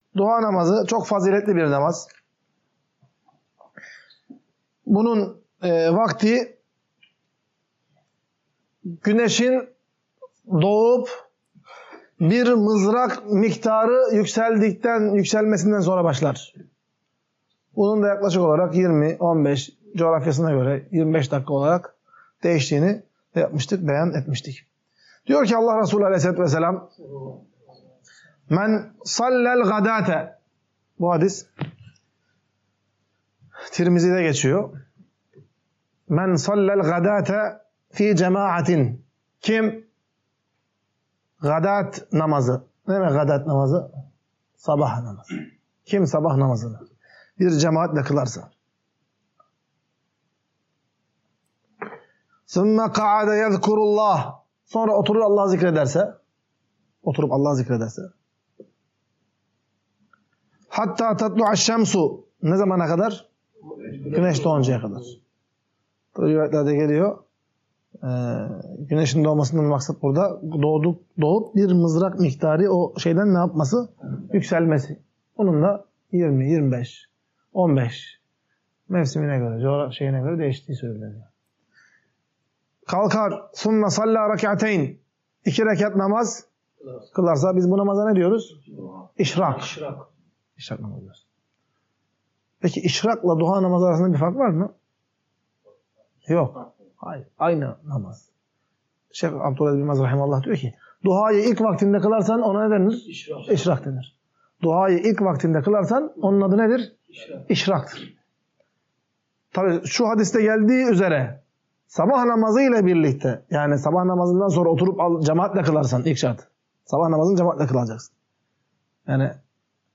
Ders - 28. BÖLÜM | KUŞLUK NAMAZININ, GÜNEŞİN YÜKSELMESİNDEN ZEVAL VAKTİNE ERMESİNE KADARKİ SÜRE İÇİNDE KILINABİLECEĞİ; ANCAK SICAĞIN ARTTIĞI VE GÜNEŞİN İYİCE YÜKSELDİĞİ VAKİTTE KILINMASININ DAHA FAZİLETLİ OLDUĞU